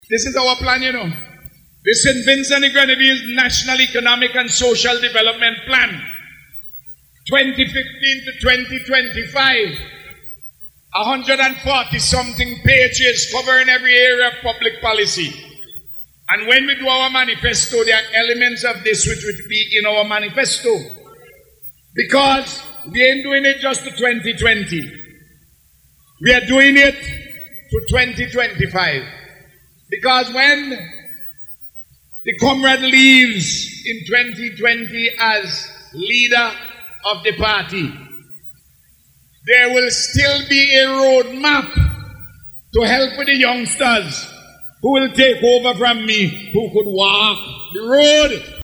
Prime Minister and Leader of the Unity Labour Party, Dr. Ralph Gonsalves highlighted the issues while addressing supporters at a Rally in Redemption Sharpes on Sunday.